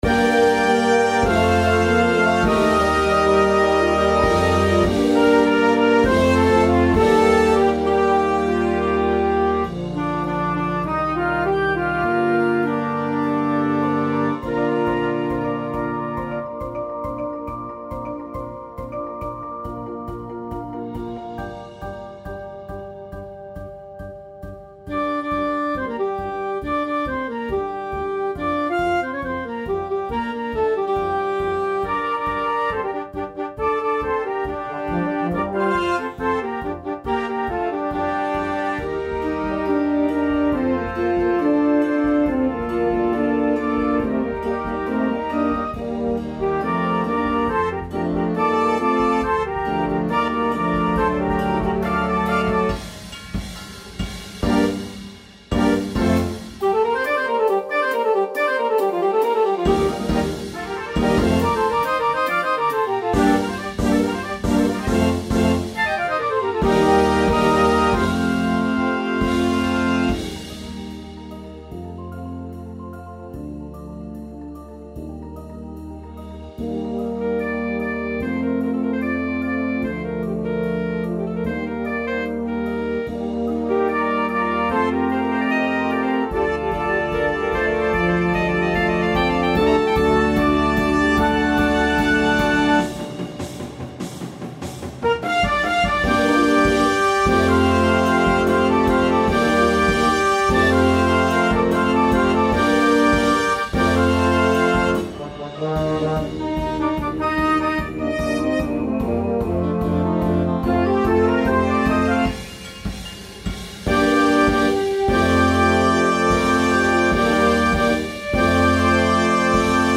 a powerful tribute to resilience and strength
With a blend of soul, pop, and attitude